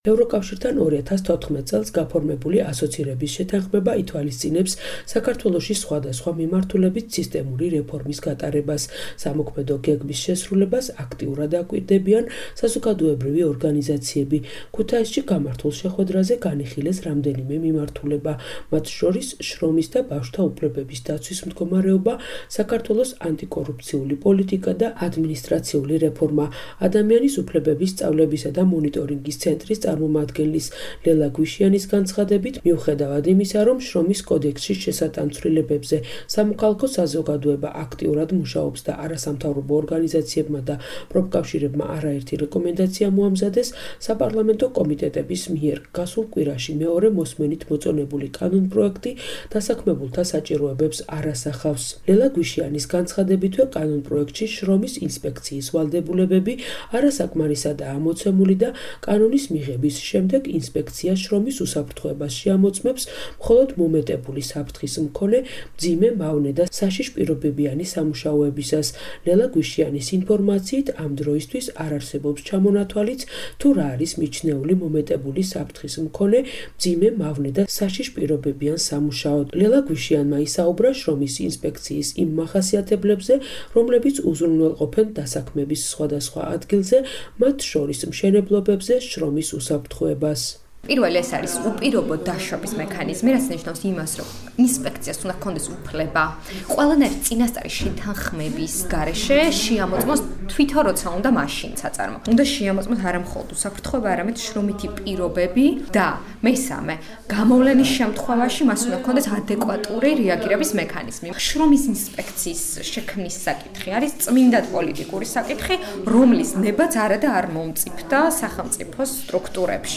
დისკუსიის მონაწილეები